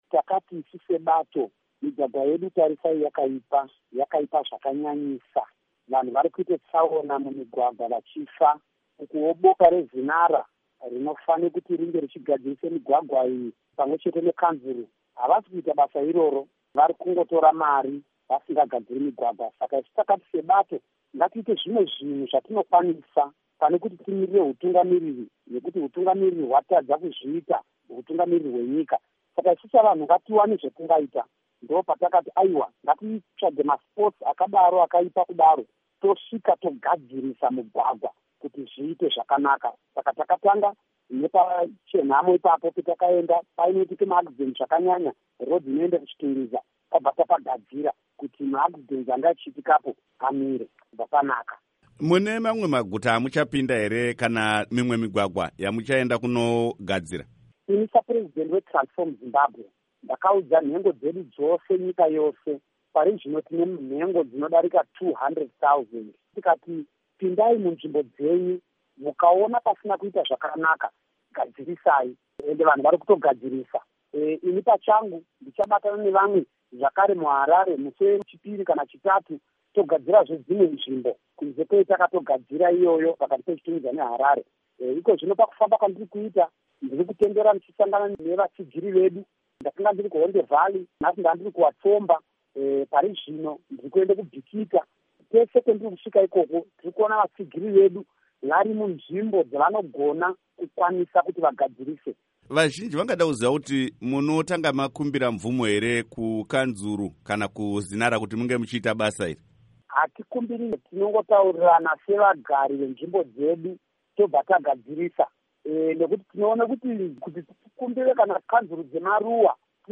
Hurukuro naVaJacob Ngarivhume